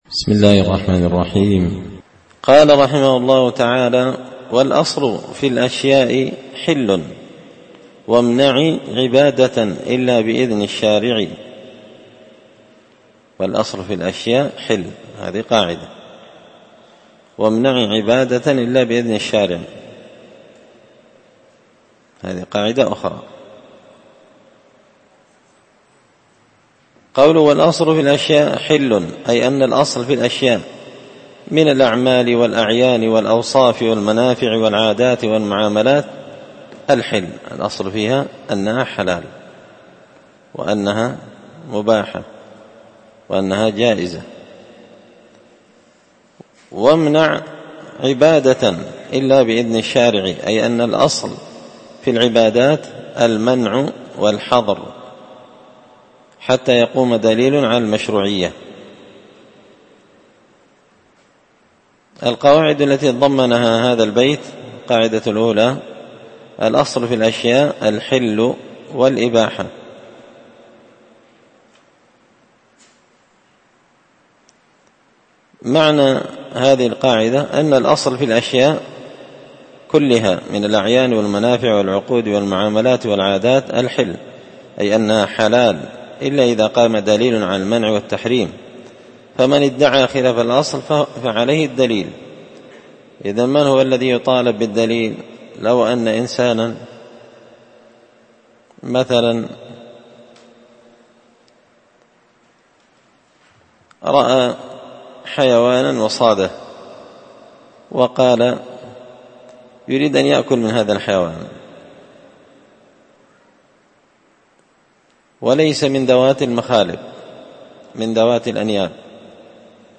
تسهيل الوصول إلى فهم منظومة القواعد والأصول ـ الدرس 14
دار الحديث بمسجد الفرقان ـ قشن ـ المهرة ـ اليمن